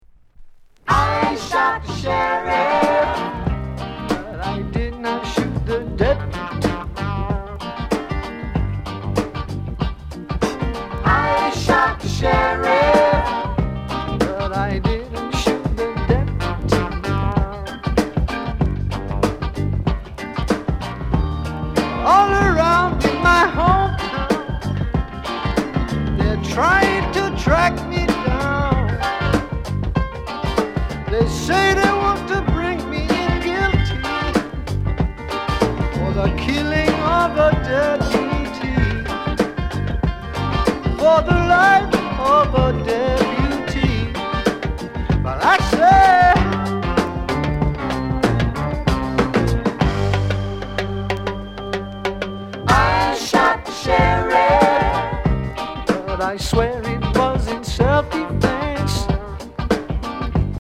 SOUND CONDITION EX-